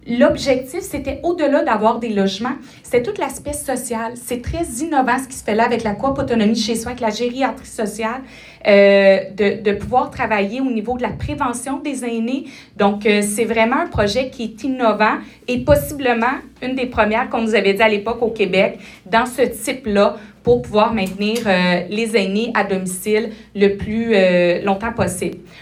La mairesse de la Ville de Granby, Julie Bourdon, soutient que le projet est important pour la ville.